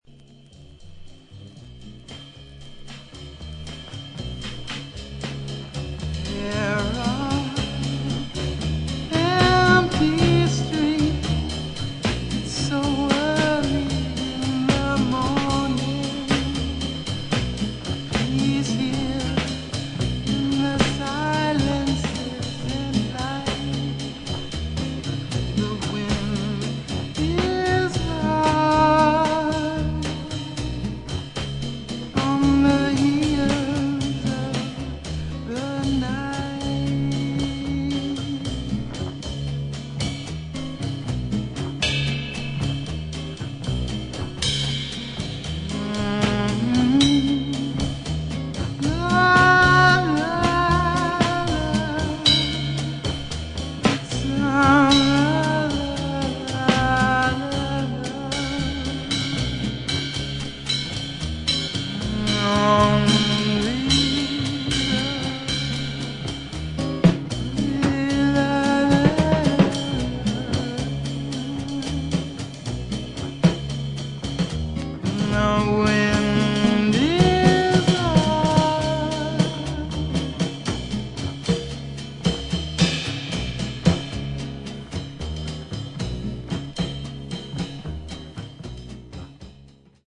アメリカン・ルーツをベースにしながらも、中東的なフレーズが印象的な楽曲など、自由度の高いセッション・フリージャズ傑作。